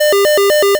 invasion_alarm.wav